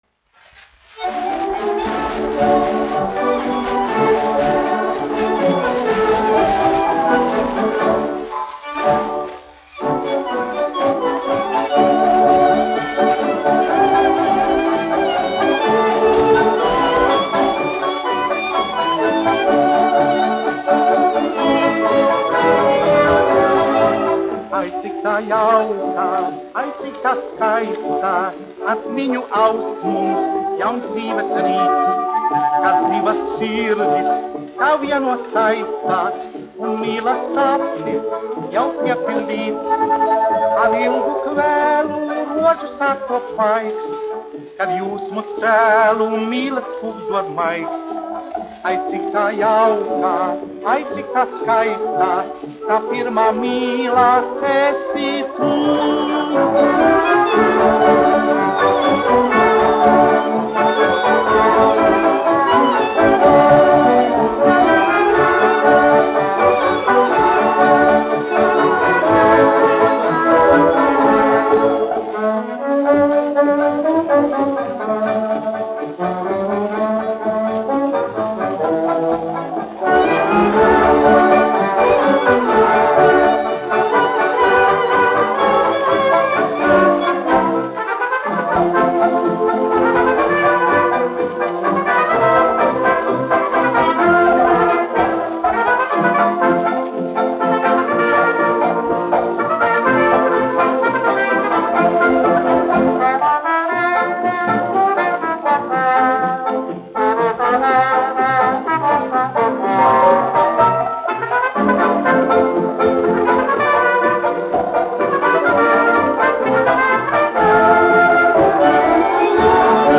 1 skpl. : analogs, 78 apgr/min, mono ; 25 cm
Fokstroti
Populārā mūzika -- Latvija
Skaņuplate